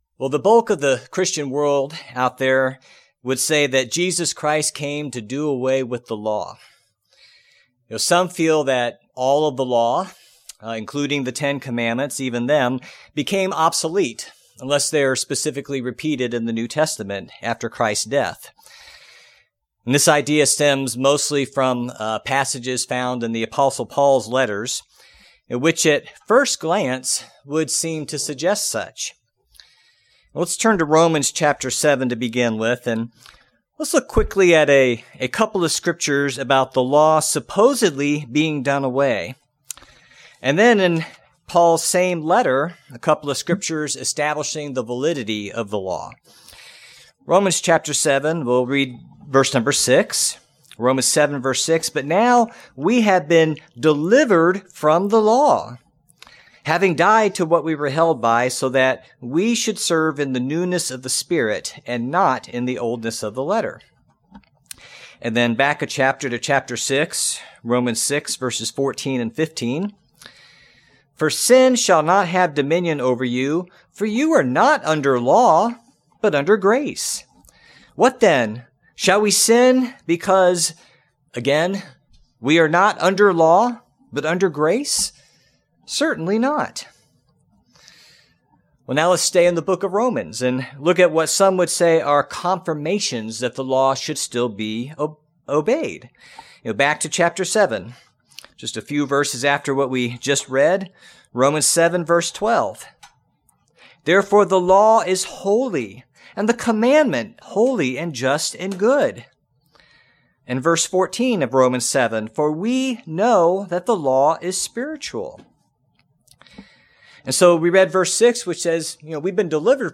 In this sermon we will discuss the various uses of nomos (law) in the New Testament, and what really happened with the law when the sacrifice of Christ occurred. We’ll talk about how we can distinguish God’s eternal spiritual law, from other types of laws when we read seemingly conflicting New Testament scriptures.